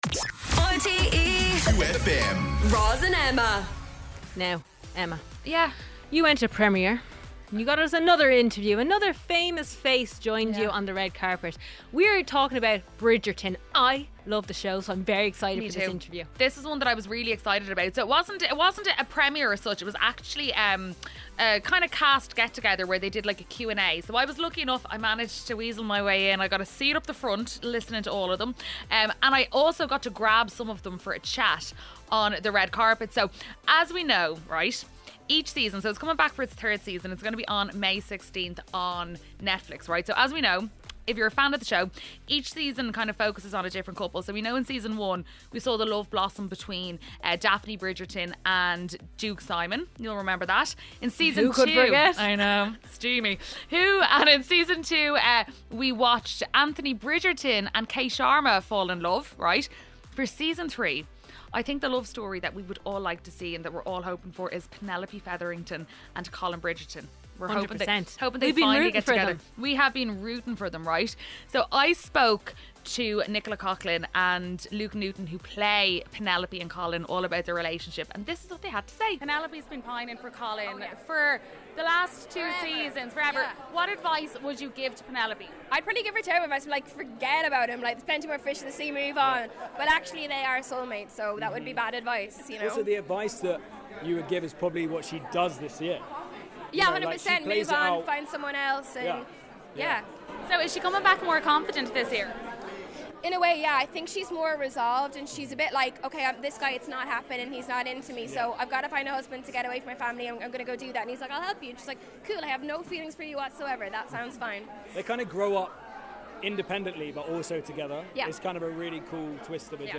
Live from Cork for Ireland v Wales U20's Six Nations, IRLWNT v Italy Preview, Allianz League Weekend, Racing - 23.02.2024